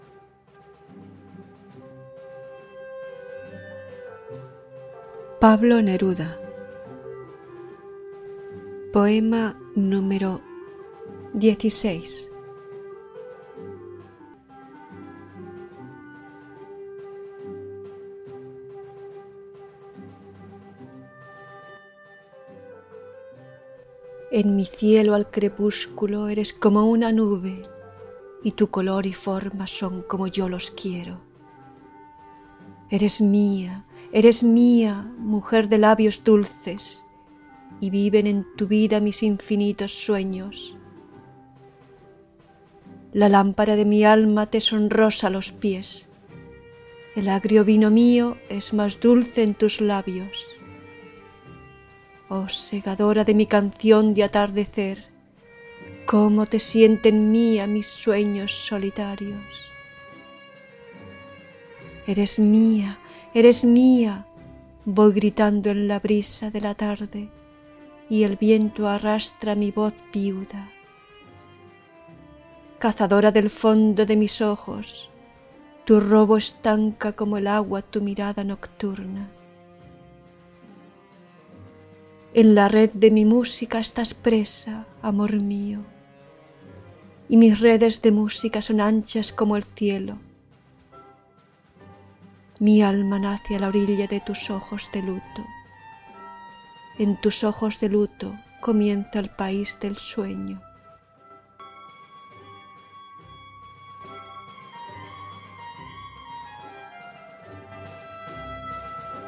(Испания)